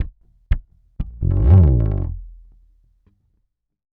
PHPH_bass_slide_01_120.wav